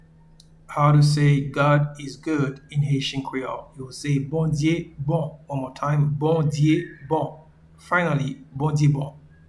Pronunciation:
God-is-good-in-Haitian-Creole-Bondye-bon.mp3